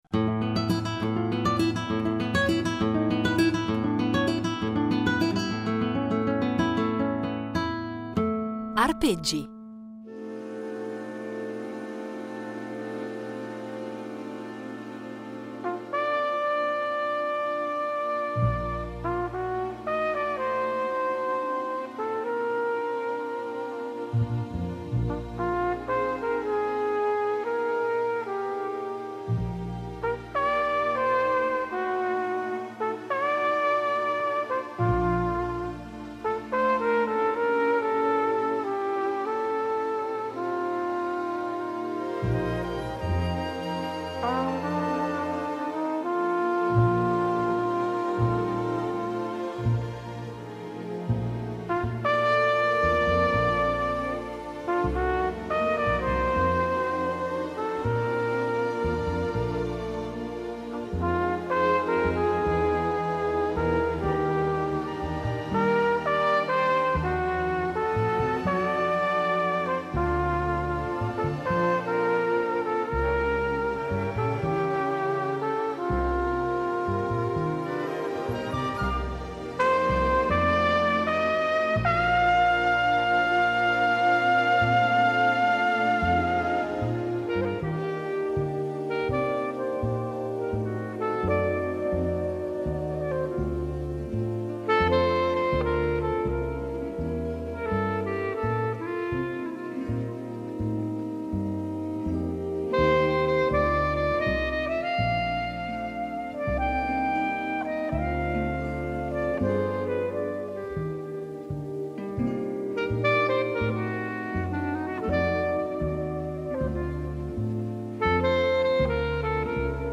Insieme, andranno quindi ad impreziosire i nostri itinerari sonori con esecuzioni inedite di canzoni che hanno contribuito a rendere indimenticabili alcune pellicole cinematografiche.